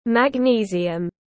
Ma-giê tiếng anh gọi là magnesium, phiên âm tiếng anh đọc là /mæɡˈniːziəm/.
Magnesium /mæɡˈniːziəm/